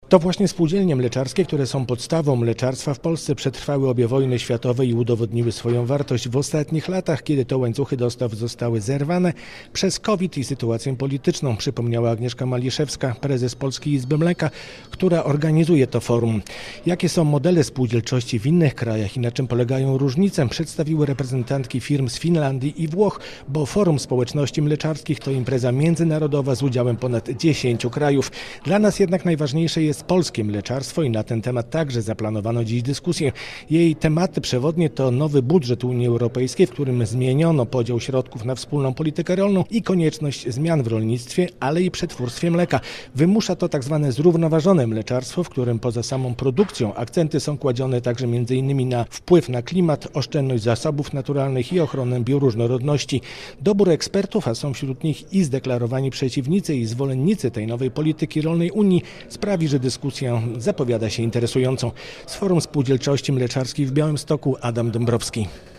O czym dyskutują uczestnicy 22. Międzynarodowego Forum Społeczności Mleczarskiej - relacja